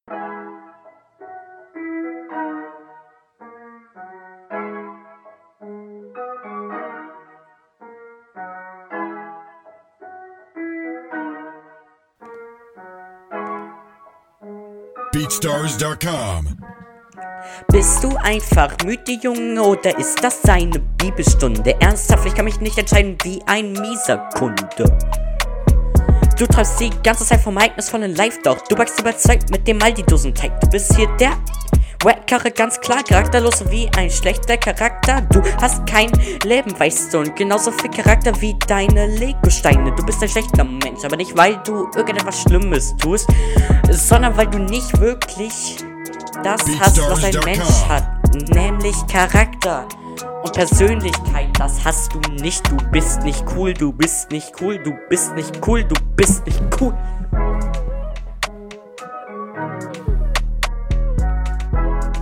Rennt ziemlich den Beat hinterher Text: keine Ahnung war Whack …